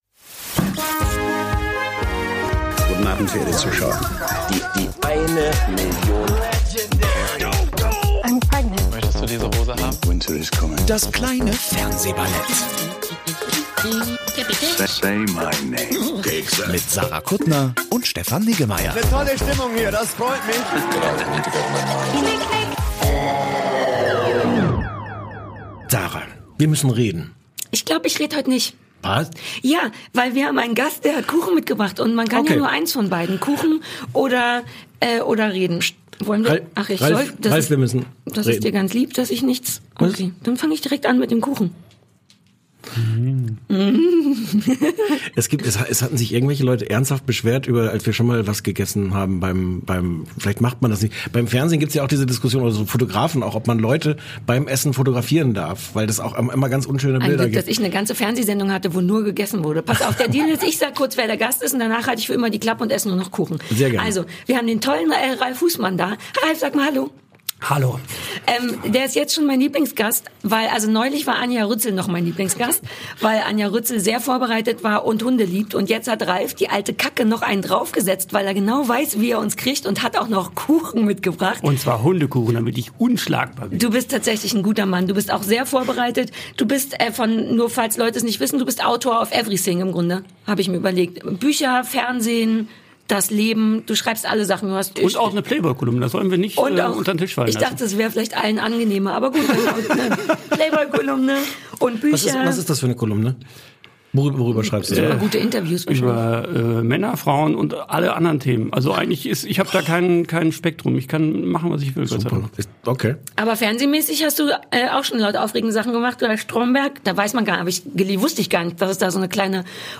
"Stromberg"-Erfinder und Drehbuchautor Ralf Husmann ist zu Gast und sie sprechen über "4 Blocks", "Mein Hund, dein Hund" und "Rectify".